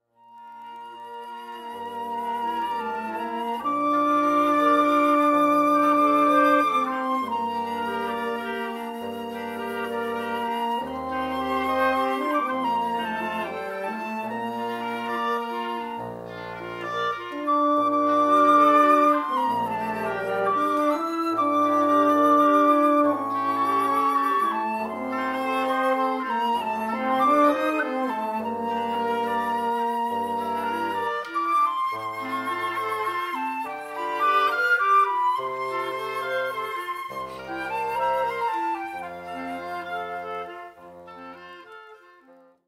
Popular prelude and ceremony music for weddings